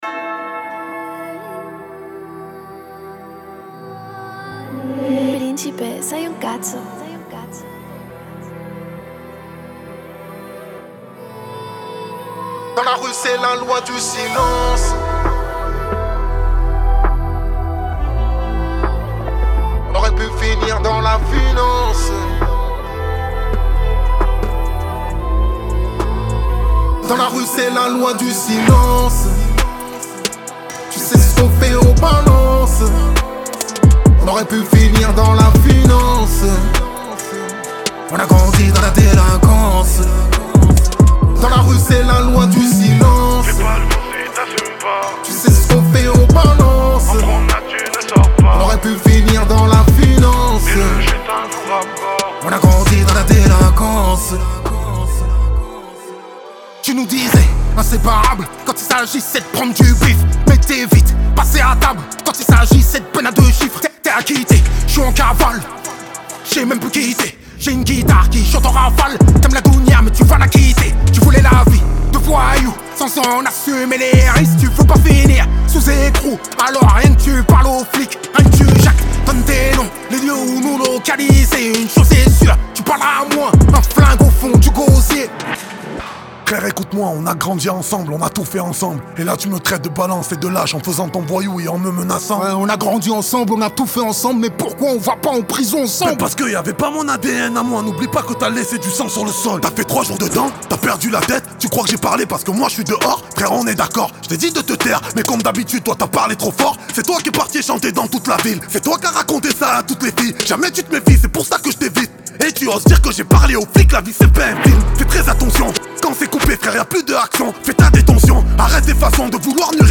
Genre : Dance